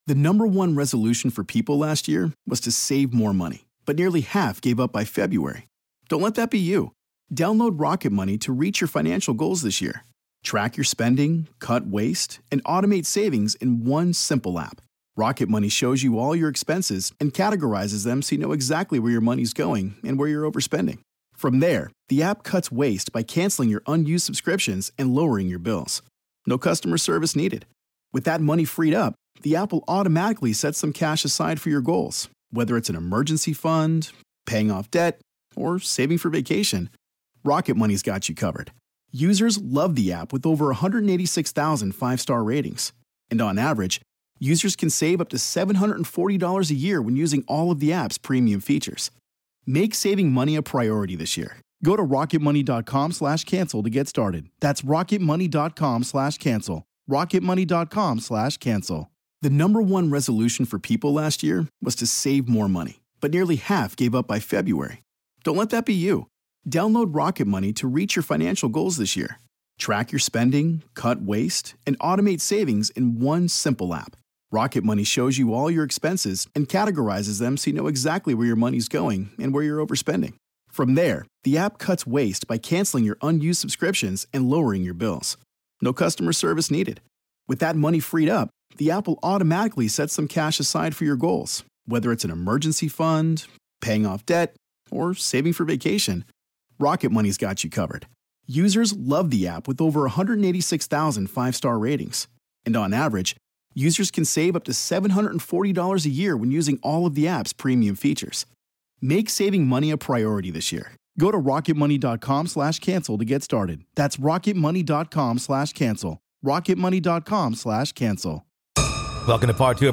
We learn about his research and experiences with the paranormal on The Grave Talks. This is Part Two of our conversation.